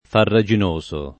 vai all'elenco alfabetico delle voci ingrandisci il carattere 100% rimpicciolisci il carattere stampa invia tramite posta elettronica codividi su Facebook farraginoso [ farra J in 1S o ] agg. — non farragginoso né faragginoso